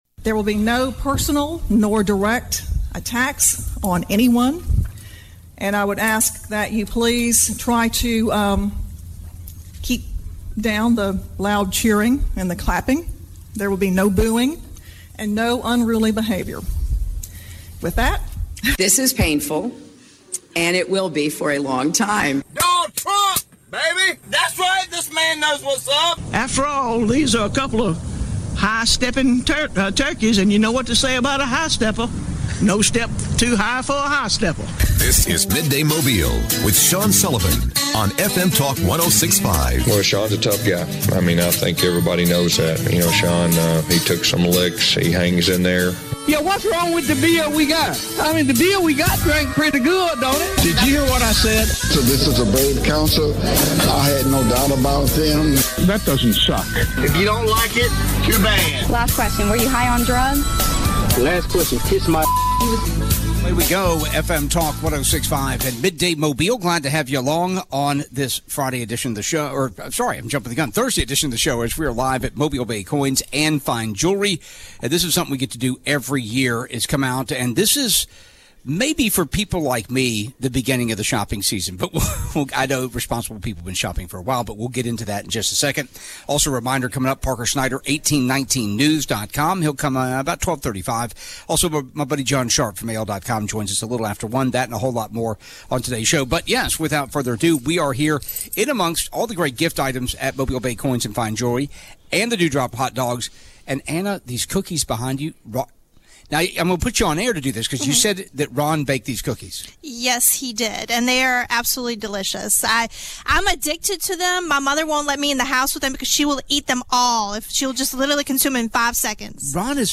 Midday Mobile - Live from Mobile Bay Coins